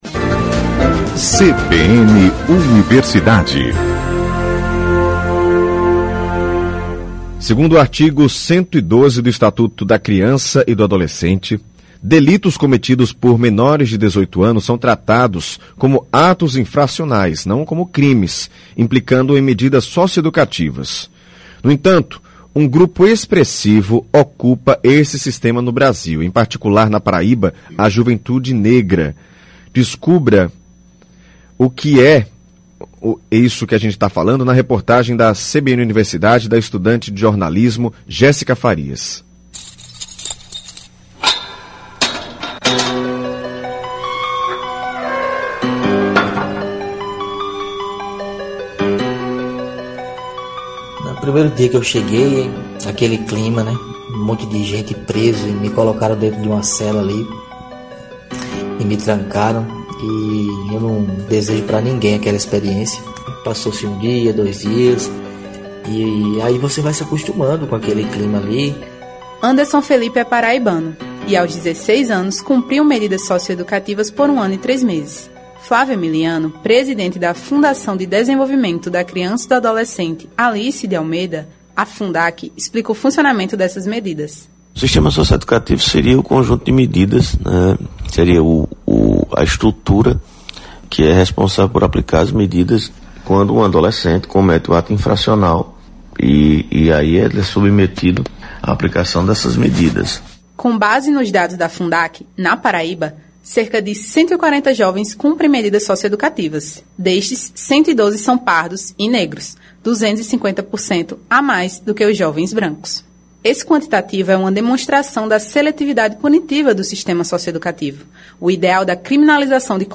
No entanto, um grupo expressivo ocupa esse sistema no Brasil e, em particular, na Paraíba: A juventude negra. É o que aponta a reportagem do CBN Universidade